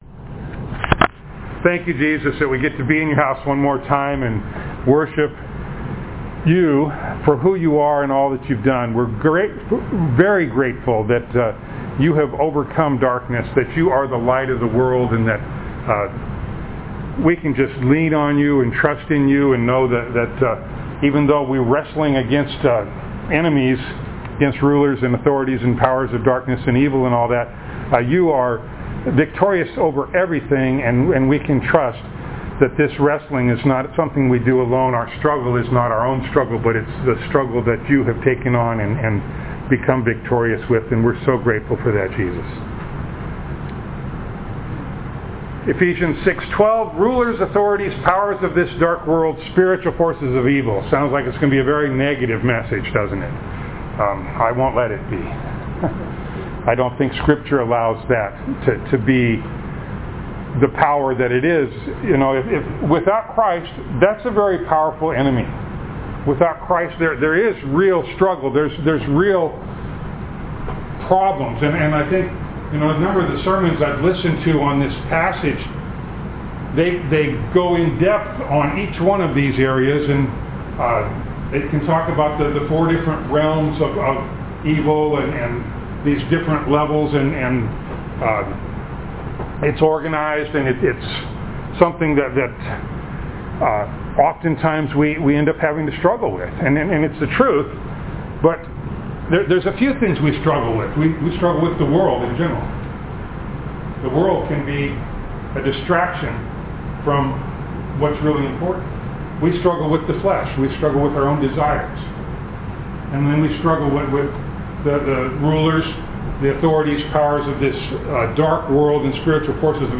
Passage: Ephesians 6:12 Service Type: Sunday Morning Download Files Notes « What are We Against?